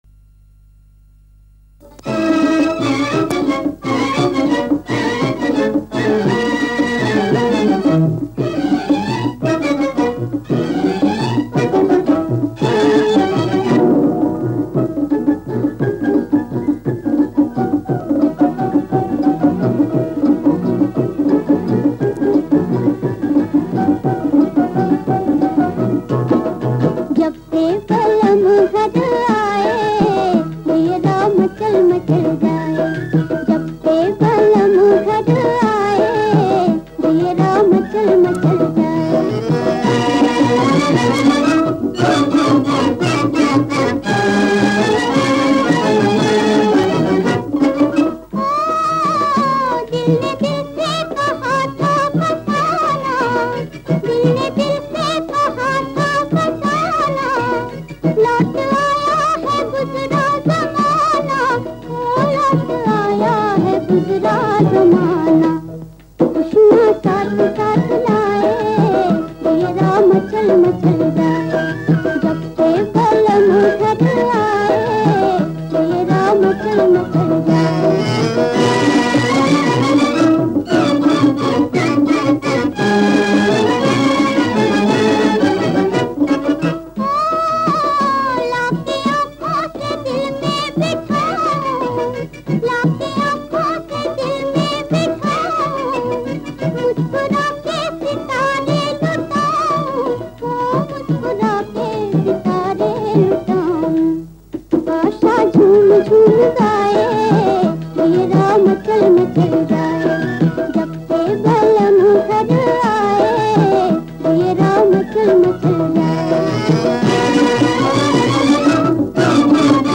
原声